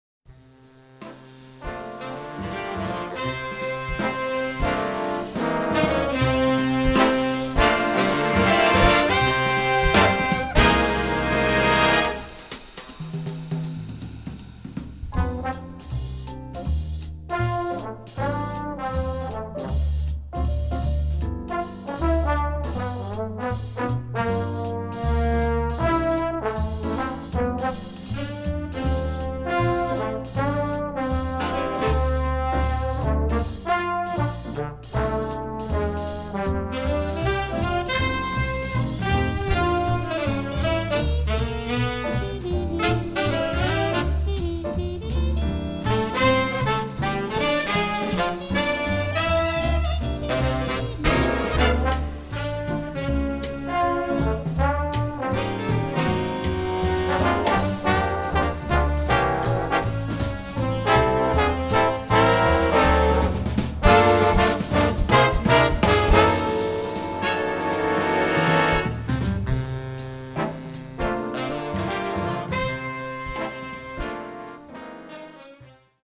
Instrumentation: Big Band (4/5 Trumpets, 4 Trombones)